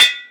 terraria_reforge.wav